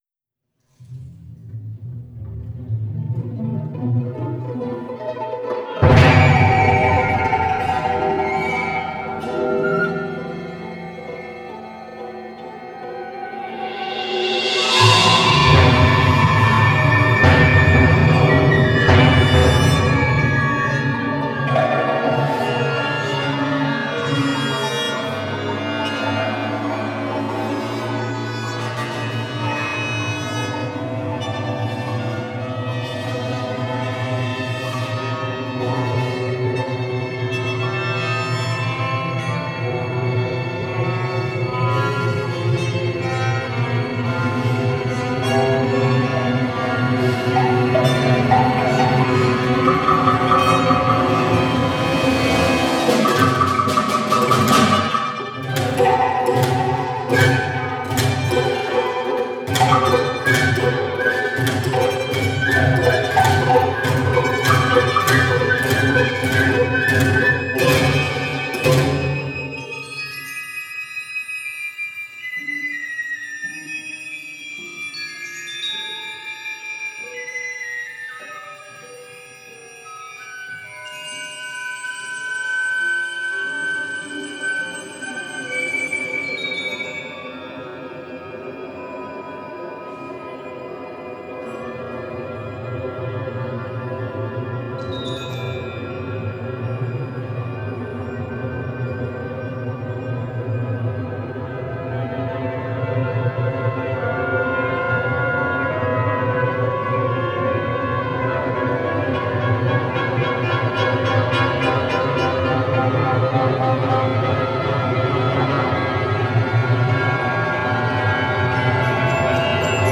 Premonition (2012) for full orchestra
“Premonition” for full orchestra was read by the University of Louisville Orchestra during Esa-Pekka Salonen’s Conducting and Composition Workshop.